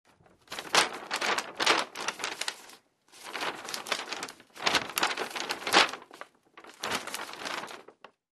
Расправляем страницы